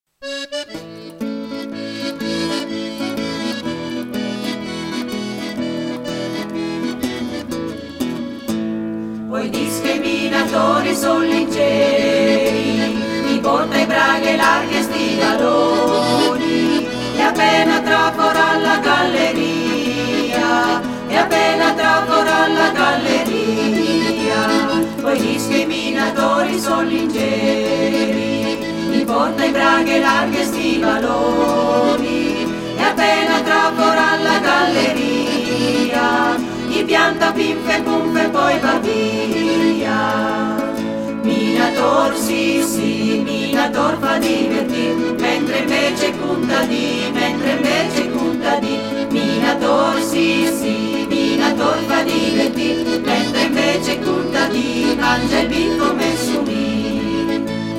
Taissine: cernitrici di minerale nelle miniere bergamasche [Gruppo folklorico]
Canto popolare in dialetto bergamasco con aggiunta di vita “Gornese”; descrive il carattere allegro e gioviale dei minatori a dispetto dei contadini preoccupati solo per il mangiare e bere.